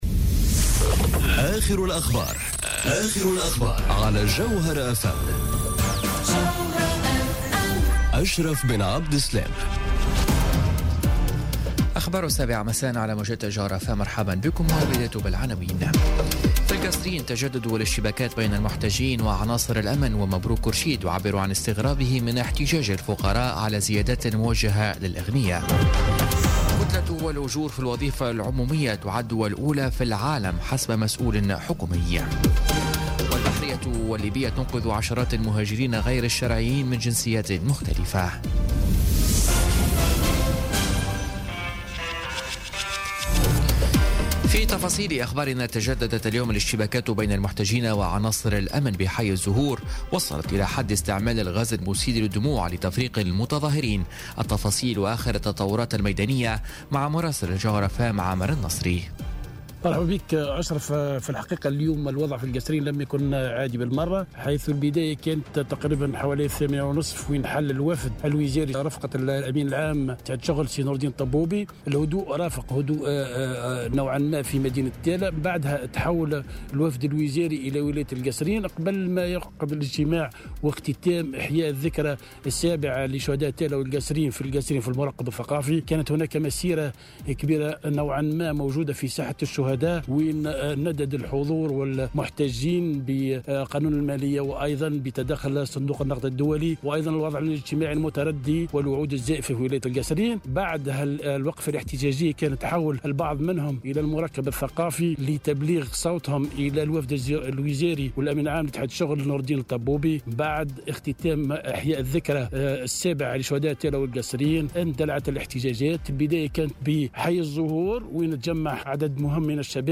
نشرة أخبار السابعة مساء ليوم الاثنين 8 جانفي 2018